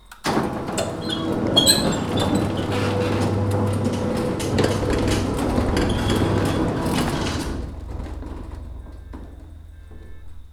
• creaky garage door sound effect short.ogg
creaky_garage_door_sound_effect_short_few.wav